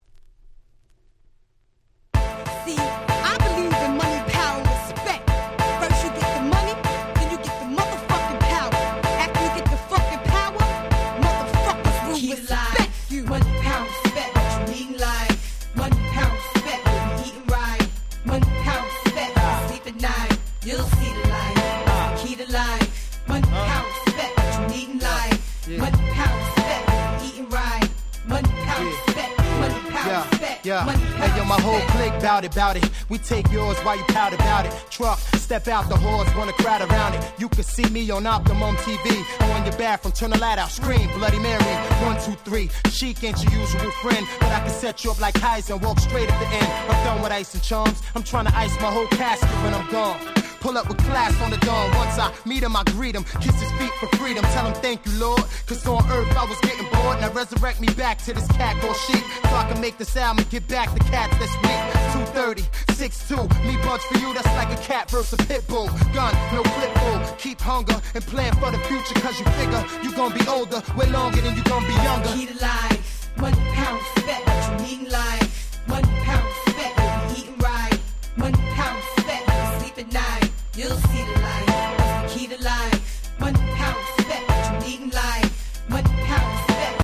98' Super Hit Hip Hop !!